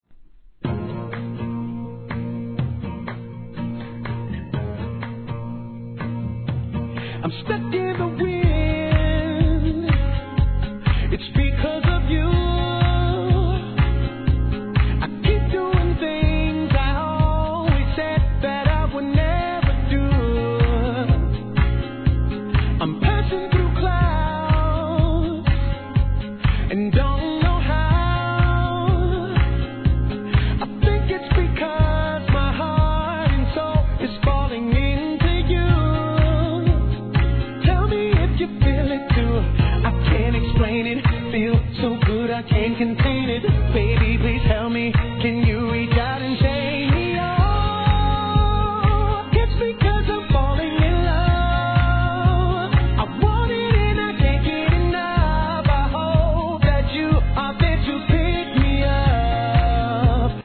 1. HIP HOP/R&B
流行に関係なく、極上美メロだけを収録のラグジュアリーR&Bコンピ!!